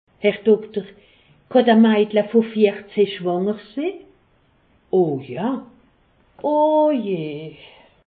Expressions populaires
Haut Rhin
Ville Prononciation 68
Pfastatt